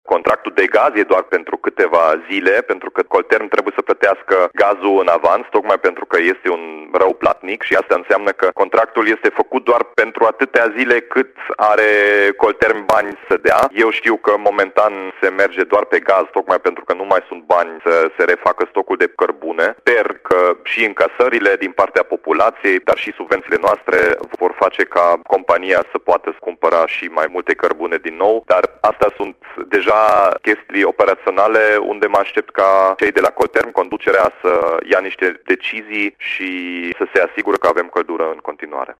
Anunțul a fost făcut la Radio Timișoara de primarul Dominic Fritz, care a precizat că societatea funcționează acum doar pe gaz metan.